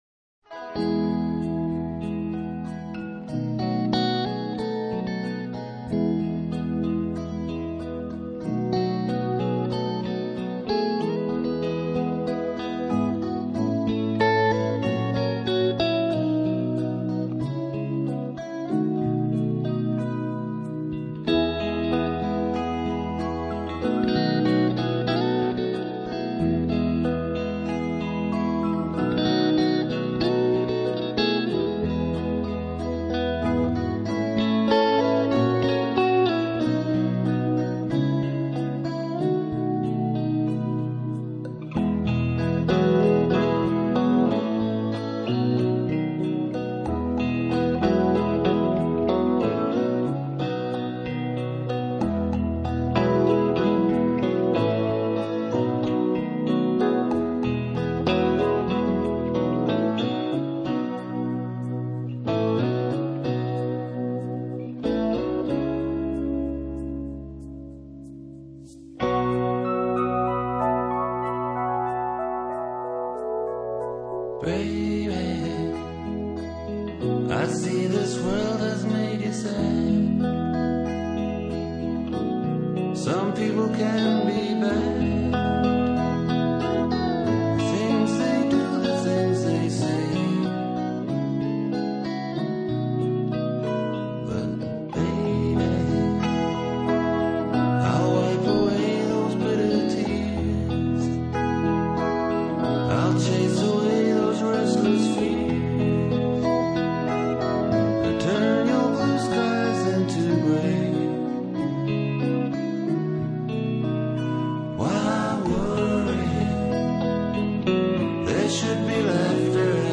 , c'est vrai qu'elle est sympa ta chanson douce